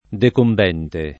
decombente [ dekomb $ nte ]